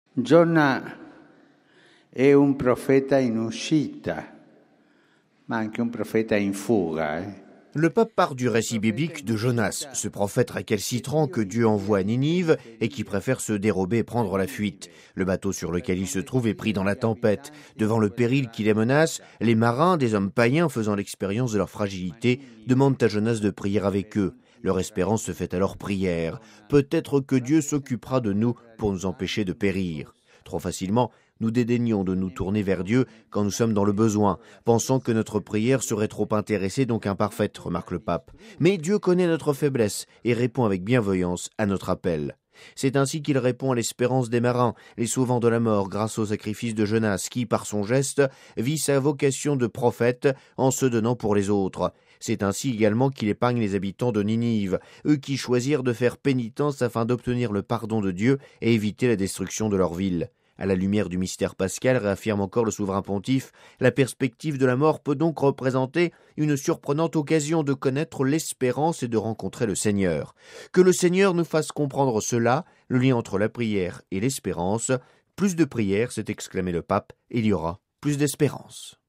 Le compte-rendu